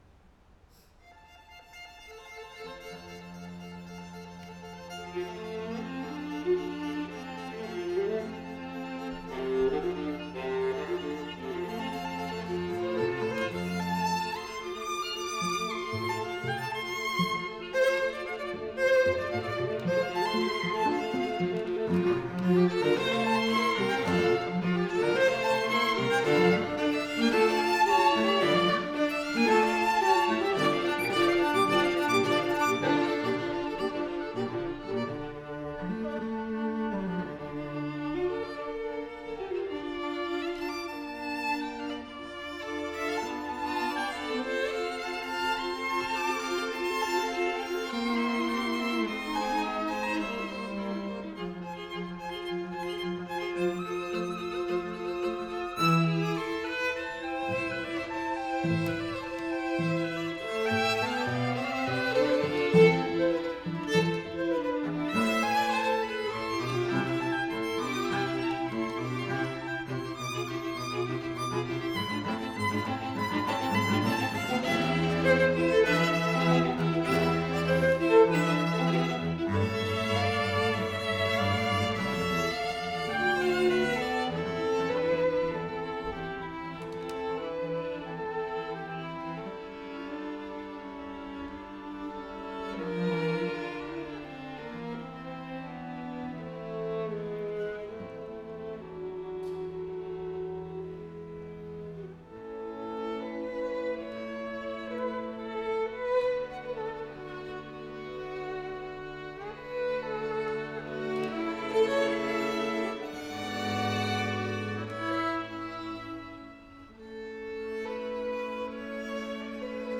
the students
Chamber Groups
Allegro ma non troppo